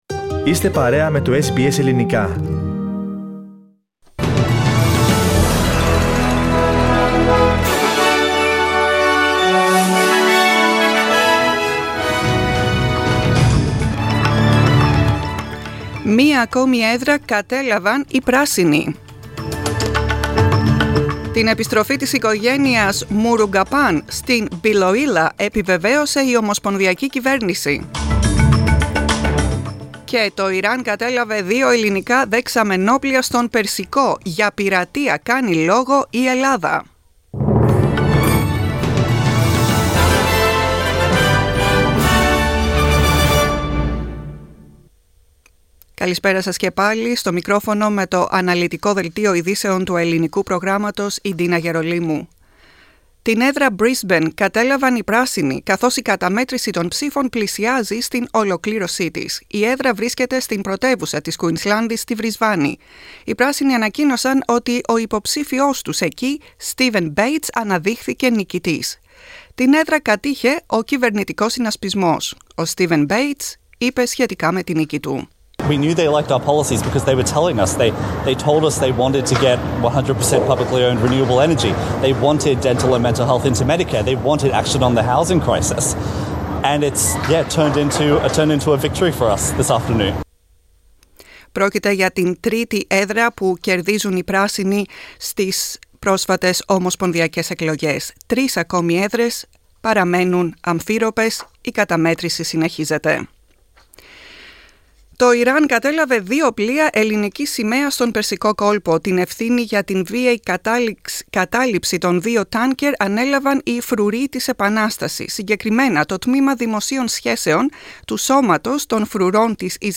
Δελτίο ειδήσεων, Σάββατο 28 Μαίου 2022